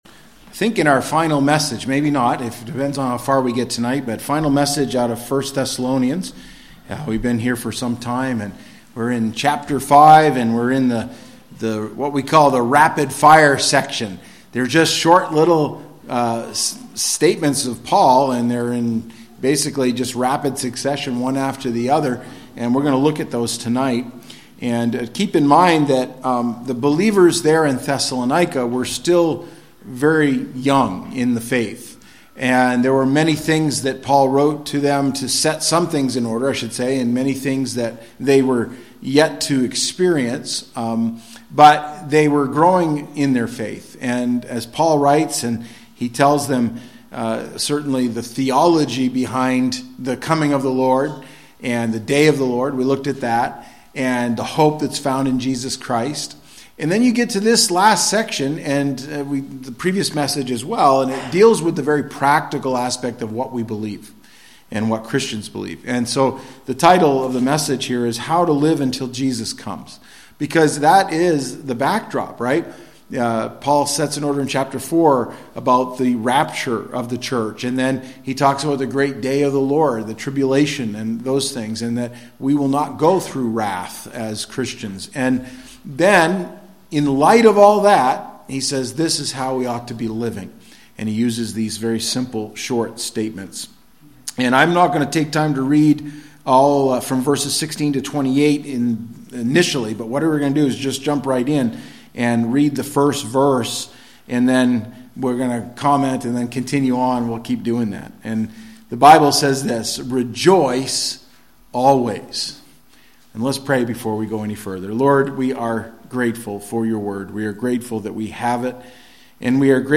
Sermons by Madawaska Gospel Church